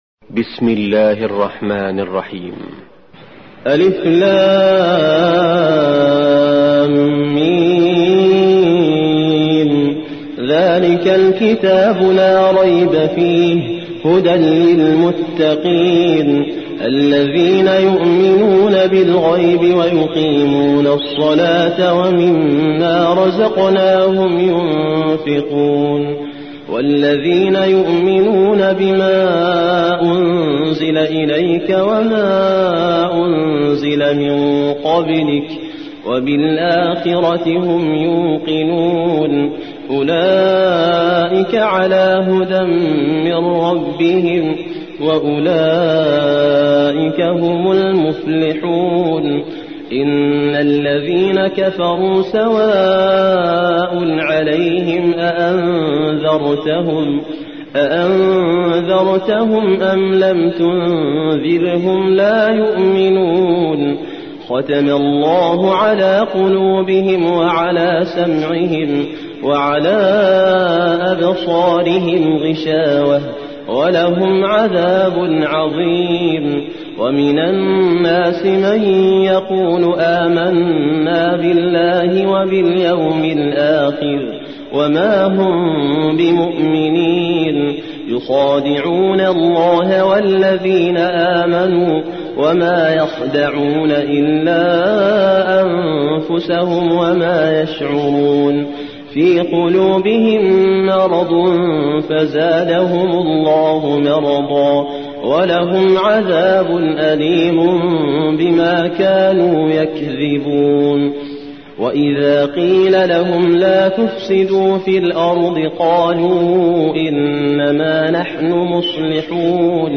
2. سورة البقرة / القارئ